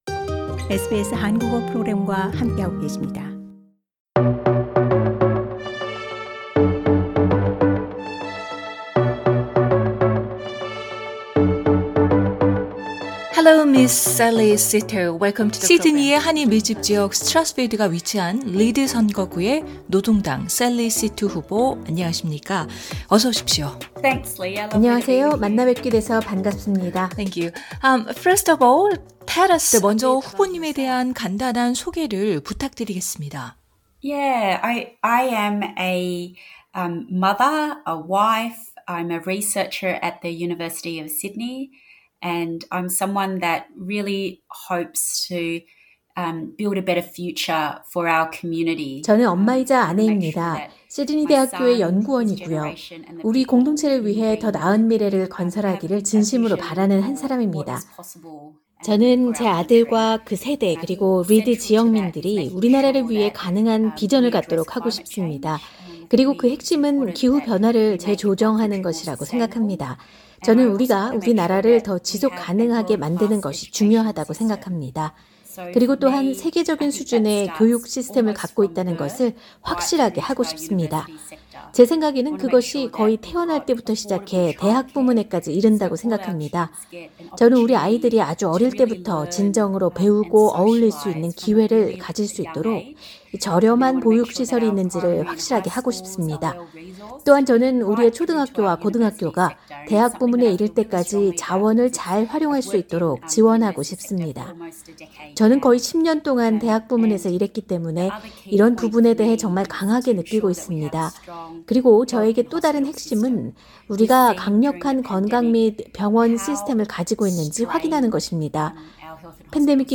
2022 연방 총선 특집 연쇄 대담: 리드 선거구 노동당 샐리 시투 후보